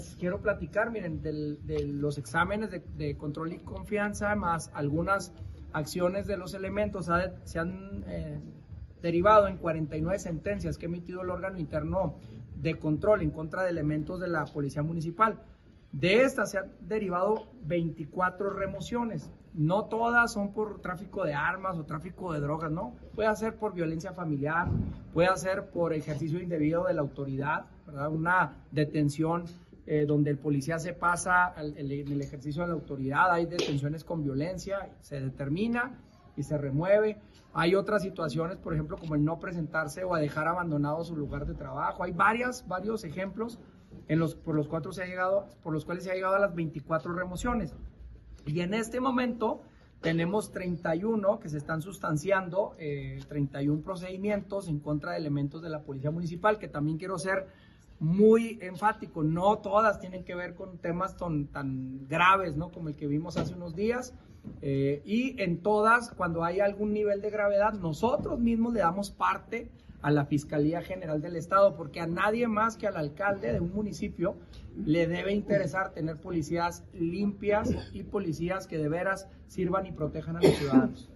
AUDIO-ALCALDE-POLICIAS-REMOVIDOS.mp3